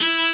b_cello1_v100l4o5e.ogg